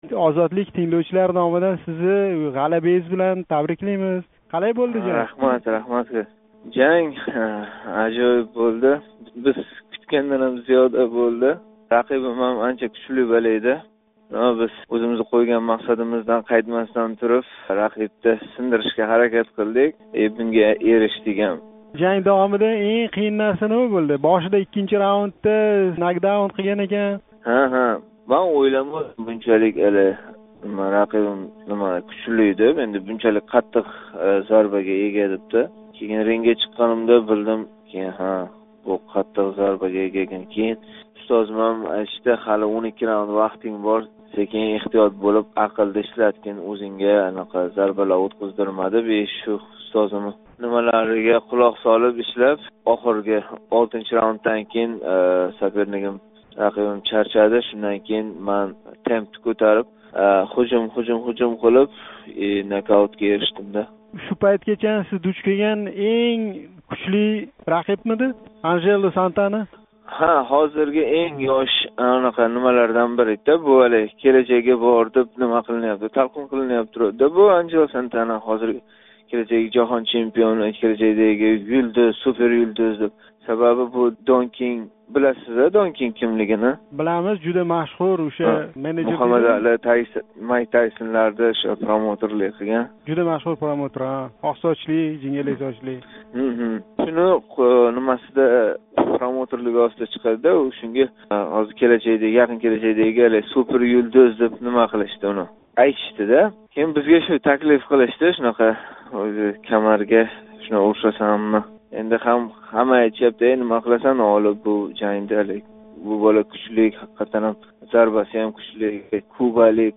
Баҳодир Мамажонов билан суҳбат.